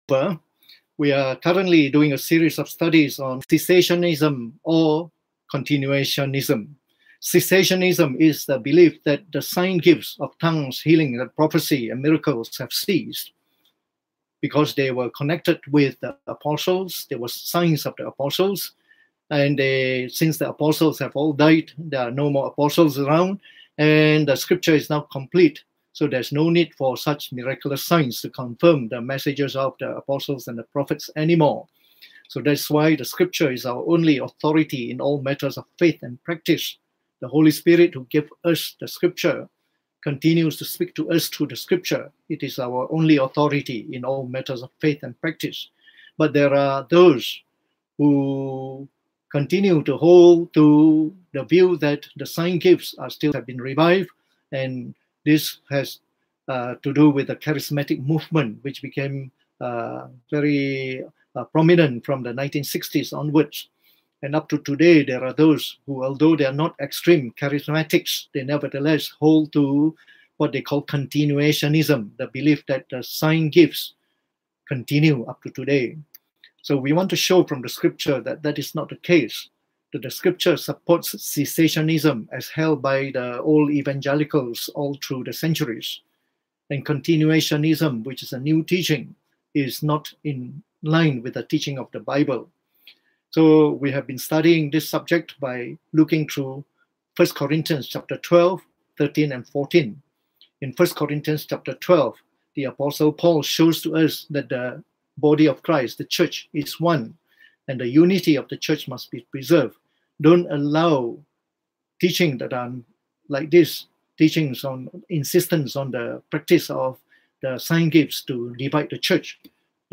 Preached on the 22nd of April 2020 during the Bible Study on Cessationism or Continuationism